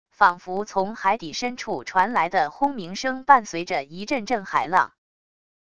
仿佛从海底深处传来的轰鸣声伴随着一阵阵海浪wav音频